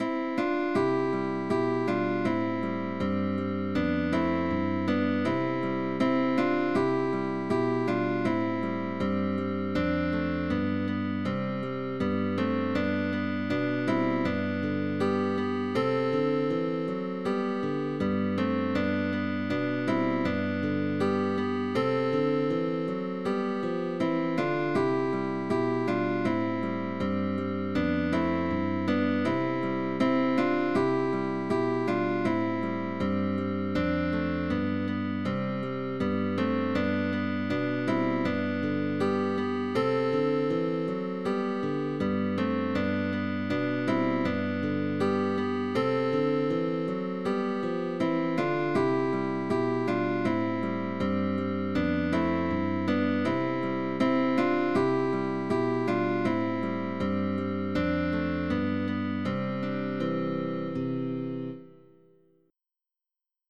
GUITAR TRIO
Spanish song from 16th century.